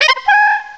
sovereignx/sound/direct_sound_samples/cries/chatot.aif at master
chatot.aif